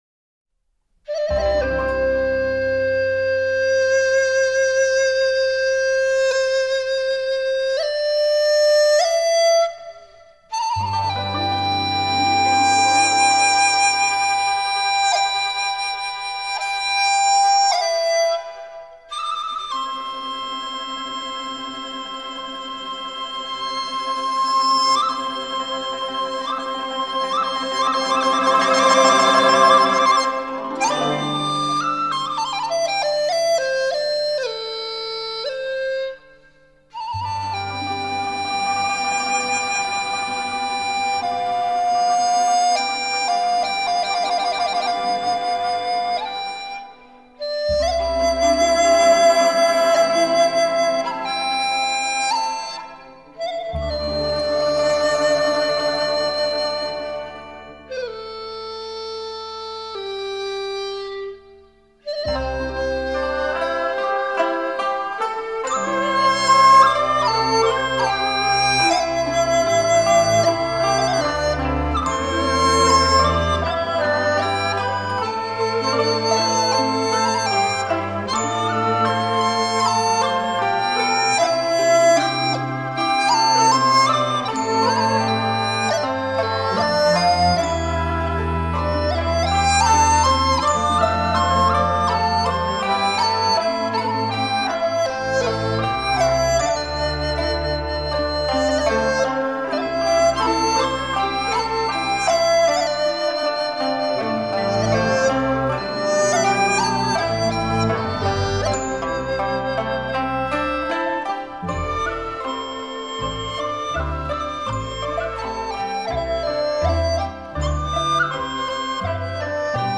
笛子独奏